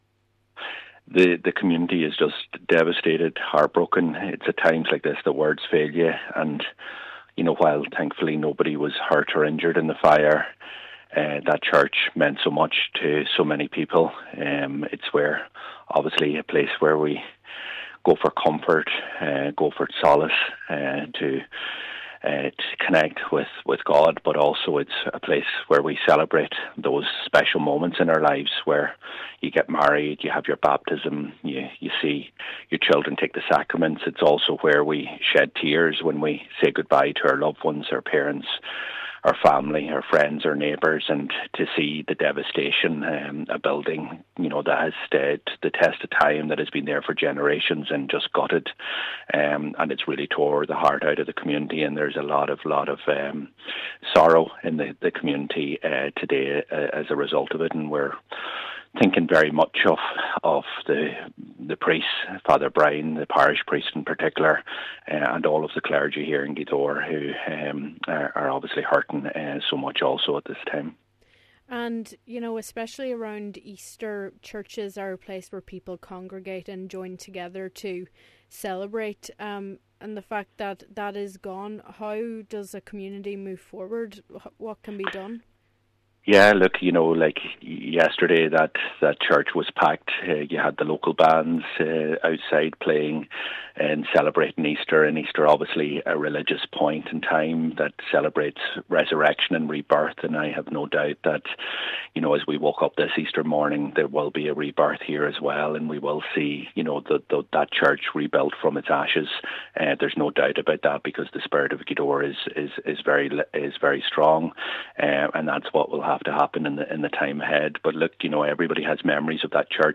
Donegal Deputy Pearse Doherty, who was due to attend his father’s anniversary mass in the chapel yesterday, spoke of the community’s devastation.